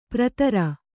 ♪ pratara